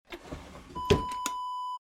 Dresser drawer close sound effect .wav #4
Description: The sound of a wooden dresser drawer being closed
A beep sound is embedded in the audio preview file but it is not present in the high resolution downloadable wav file.
Keywords: wooden, dresser, drawer, push, pushing, close, closing
drawer-dresser-close-preview-4.mp3